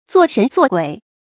做神做鬼 注音： ㄗㄨㄛˋ ㄕㄣˊ ㄗㄨㄛˋ ㄍㄨㄟˇ 讀音讀法： 意思解釋： 指鬼鬼祟祟；使用欺騙的手段。